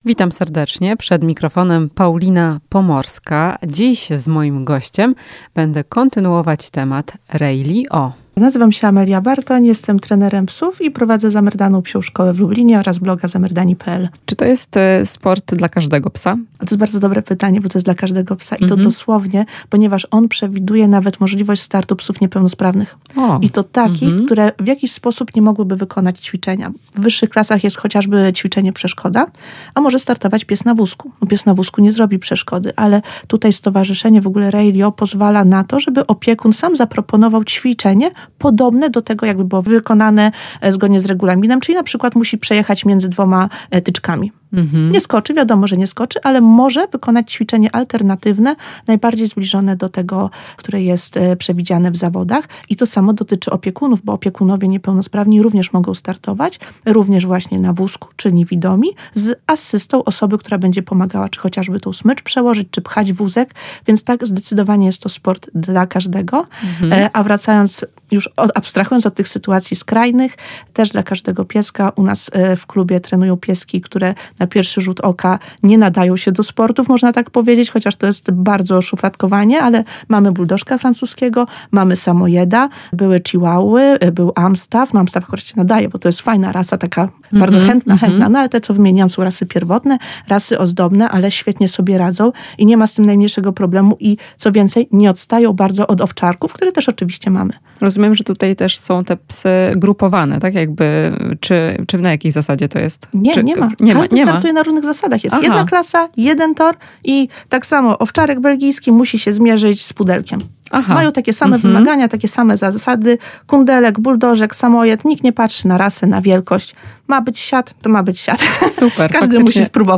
W "Chwili dla pupila" kontynuujemy temat Rally-O. Czy jest to sport dla każdego? Rozmowa